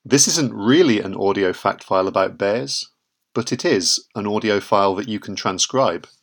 The following audio clip contains a fact file providing more details about bear mating rituals, along with samples and quotes from experts.